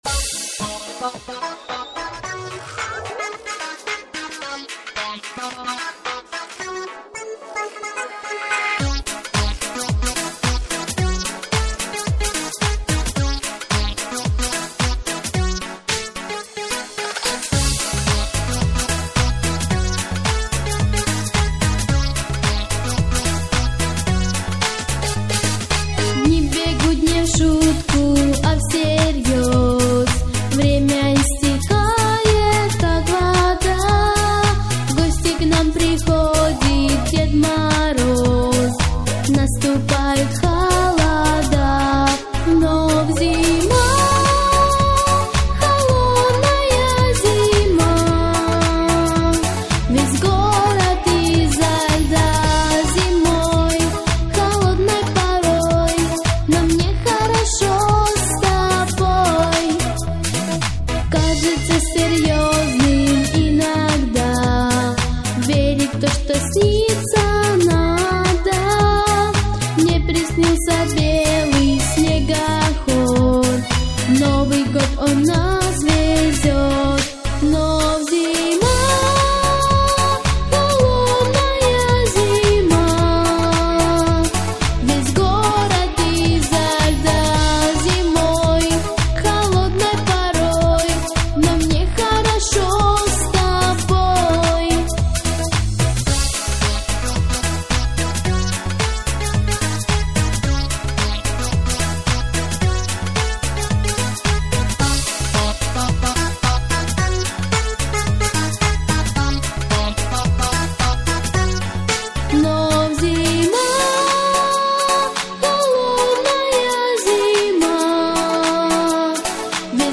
Авторська дитяча новорічна пісня середнього рівня виконання
Плюсовий запис